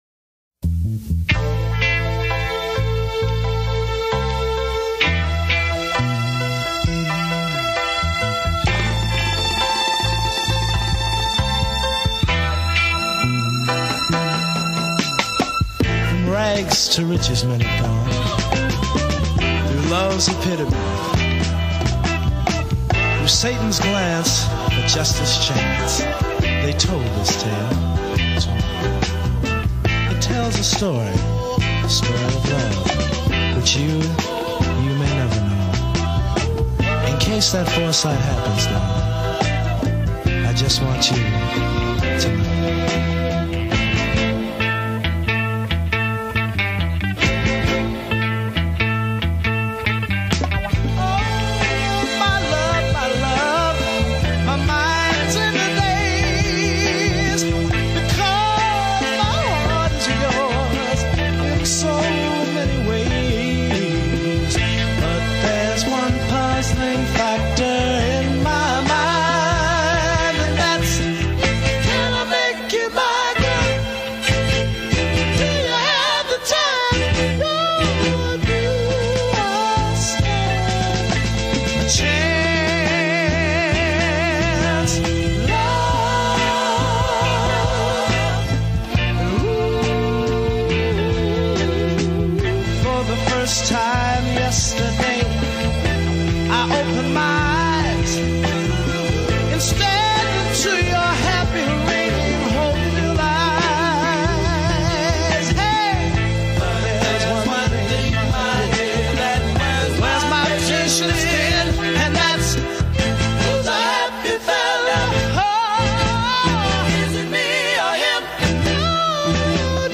rich harmonic sweeps and powerful string arrangements